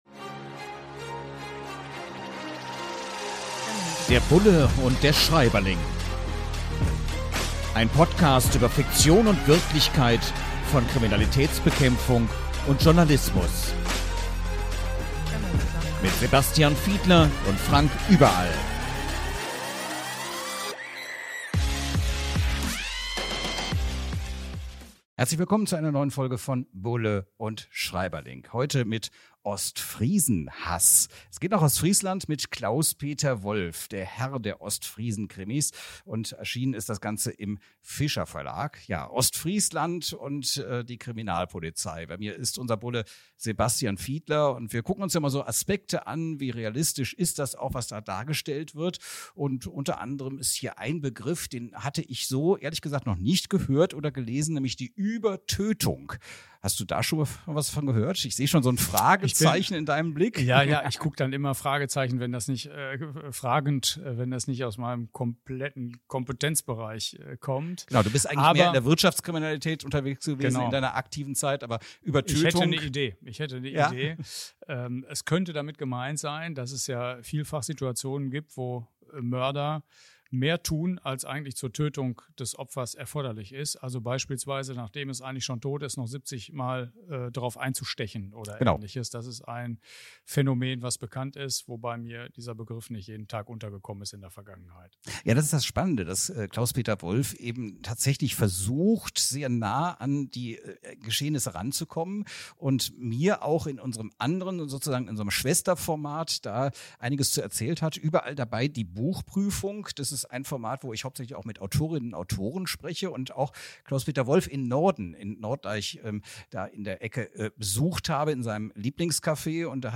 Es gibt auch ein Statement von Autor Wolf selbst. Unter anderem geht es um den Fachbegriff der „Übertötung“ – und über unbändige Freude, wenn man als Polizistin oder Polizist zum ersten Mal an einer Razzia teilnehmen darf…